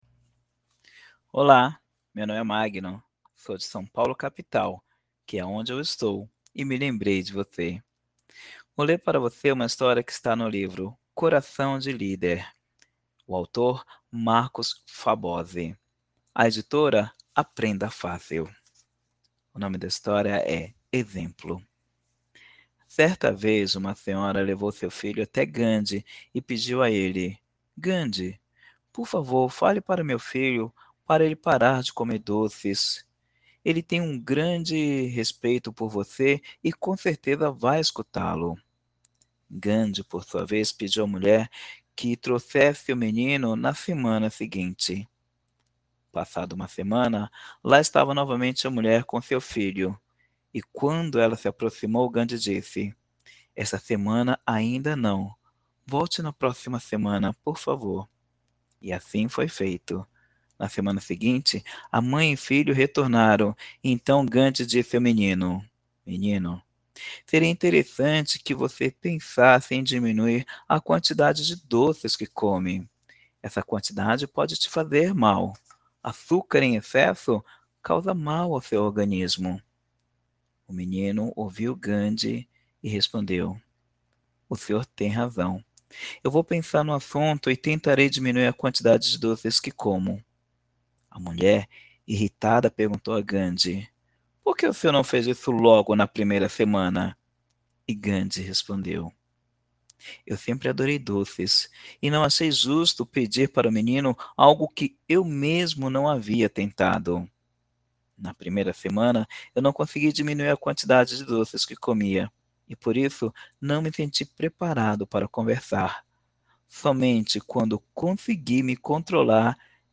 Trecho do livro “Coração de Líder”